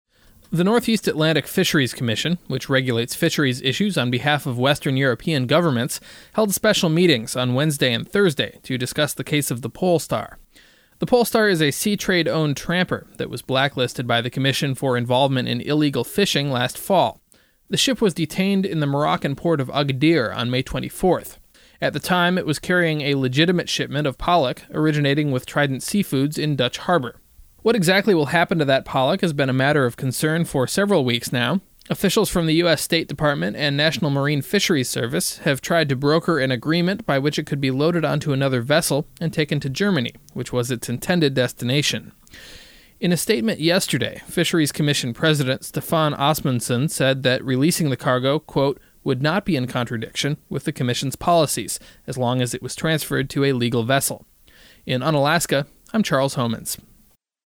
By KIAL News